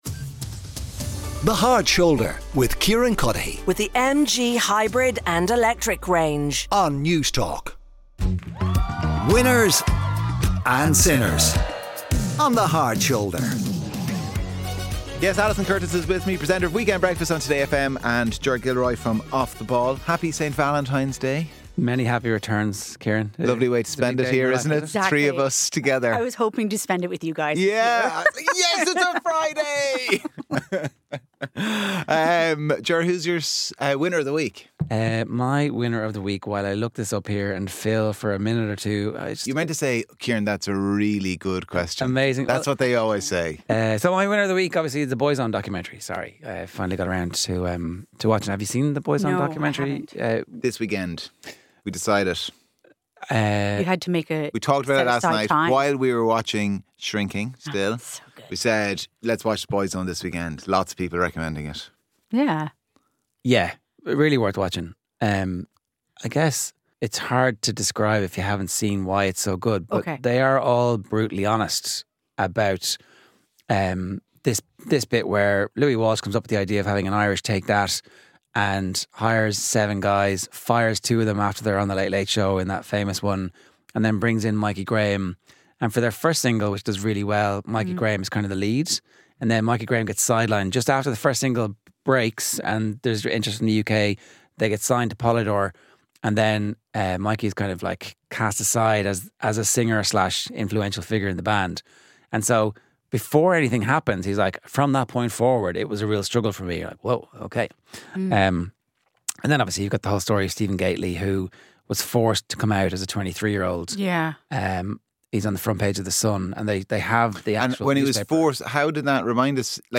interviews with the people at the centre of the stories